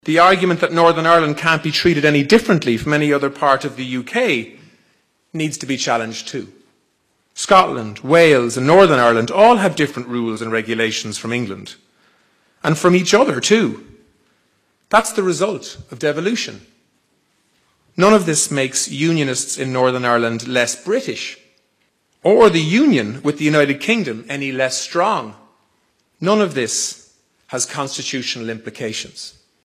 Simon Coveney was speaking at the MacGill summer school in Donegal last night, where he said progress on the Irish issue has been slower than they wanted.